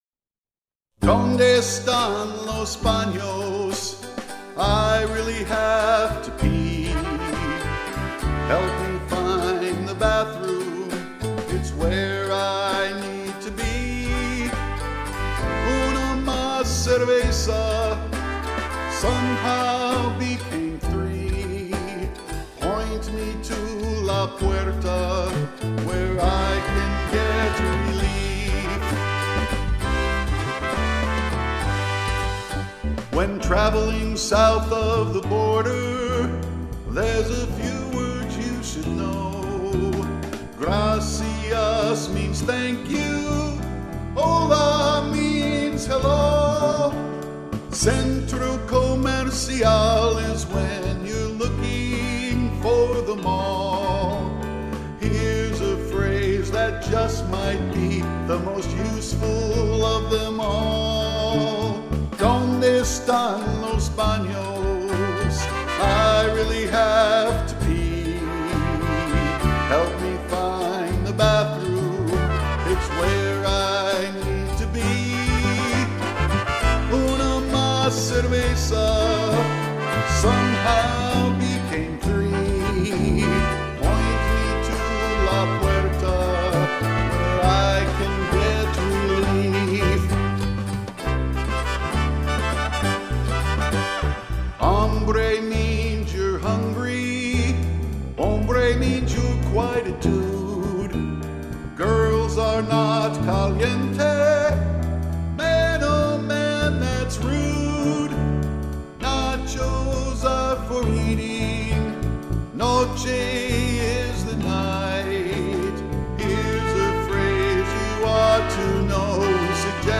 bass
piano
trumpet